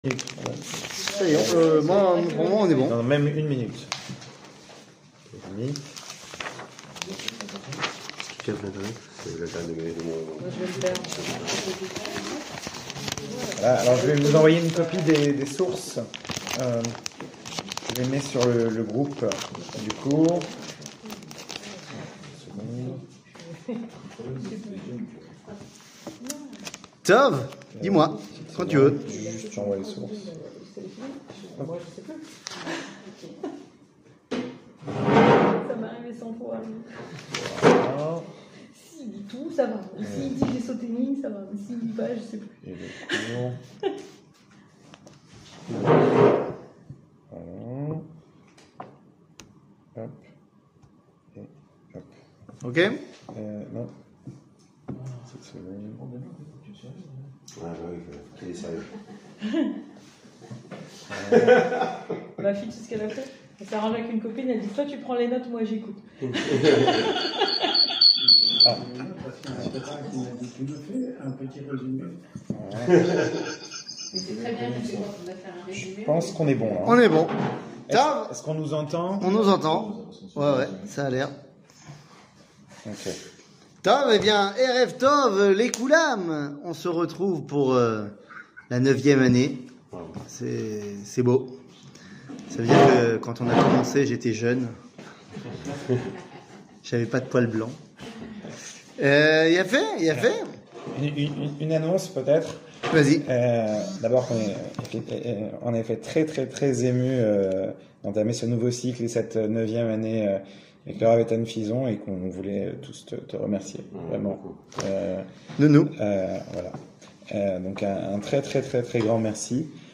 שיעור מ 12 ספטמבר 2022 55MIN הורדה בקובץ אודיו MP3 (50.69 Mo) הורדה בקובץ וידאו MP4 (115.14 Mo) TAGS : שיעורים קצרים